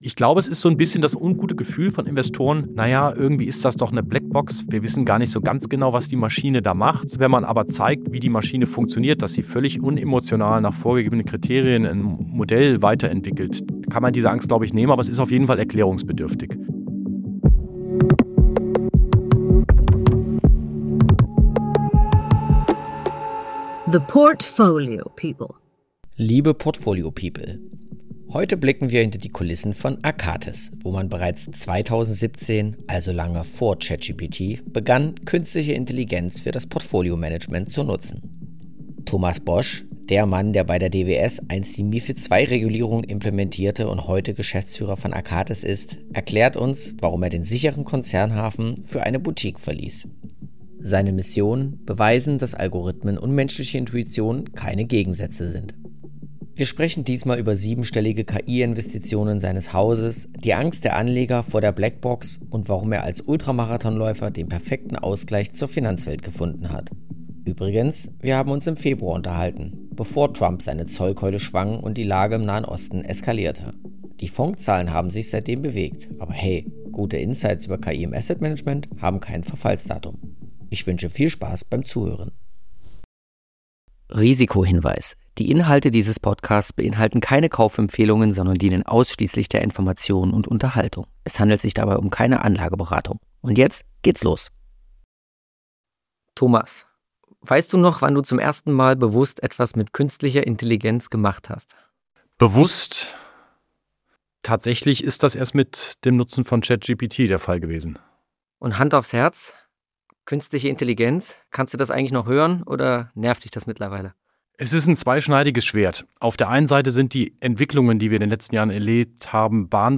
Ein ehrliches Gespräch über die Balance zwischen Mensch und Maschine, warum kleine Asset Manager im KI-Zeitalter Vorteile haben können und weshalb ein Ultramarathon-Läufer die perfekte Besetzung für diese Aufgabe ist.